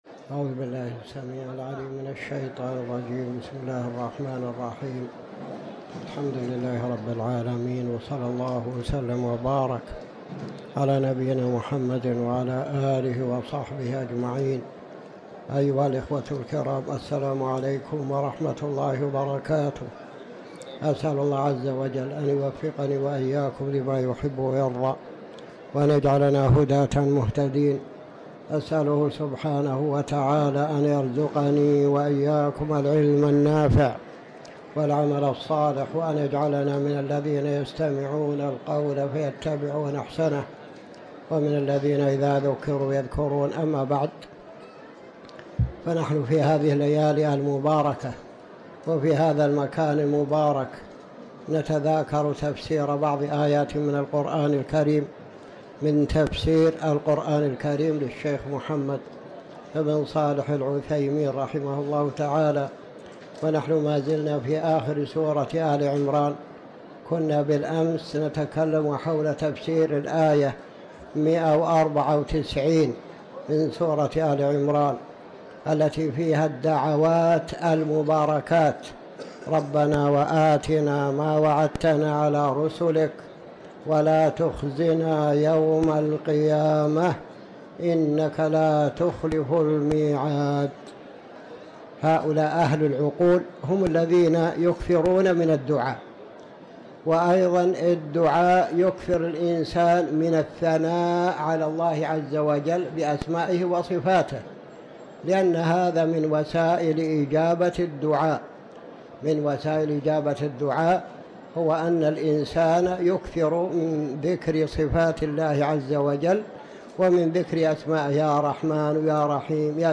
تاريخ النشر ٢٥ رجب ١٤٤٠ هـ المكان: المسجد الحرام الشيخ